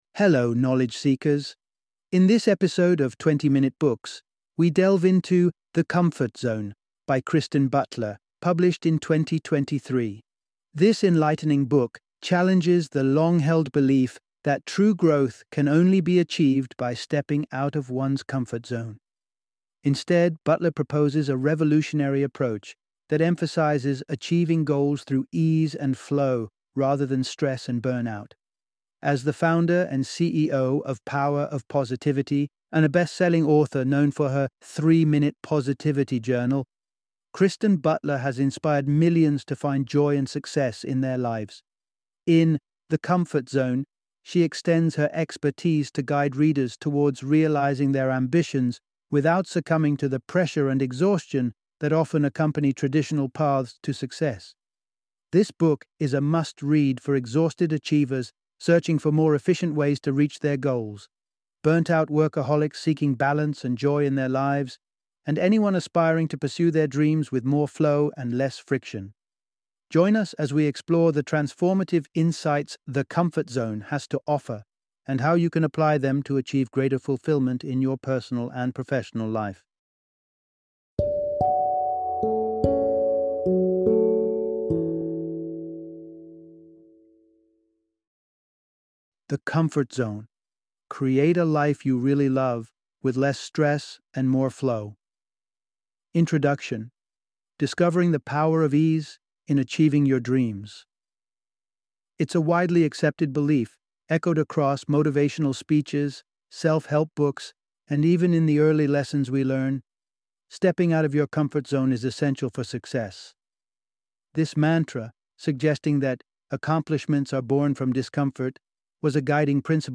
The Comfort Zone - Audiobook Summary